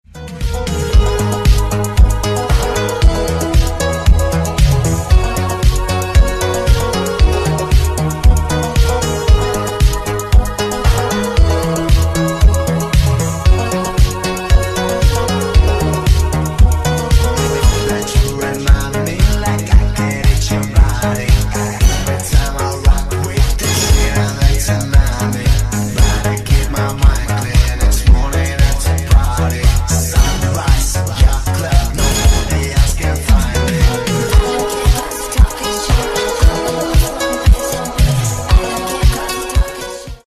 Рингтоны Ремиксы
Танцевальные Рингтоны